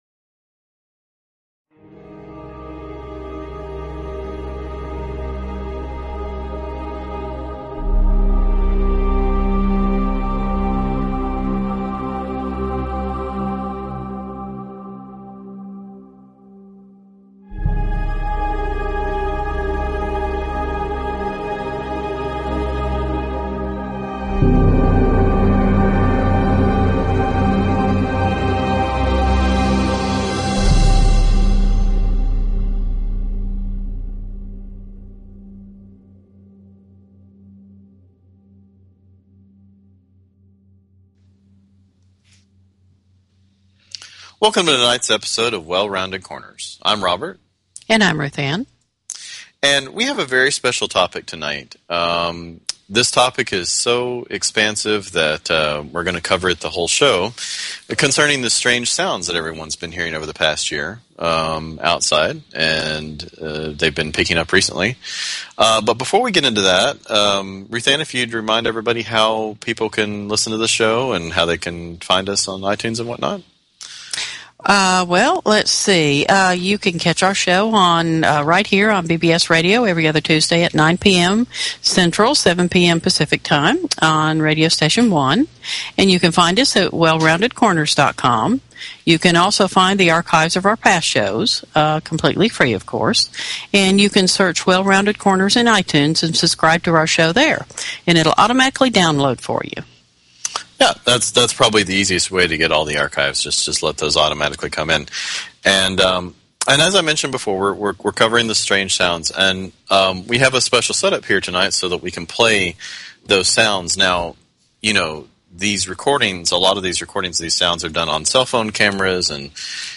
Talk Show Episode, Audio Podcast, Well_Rounded_Corners and Courtesy of BBS Radio on , show guests , about , categorized as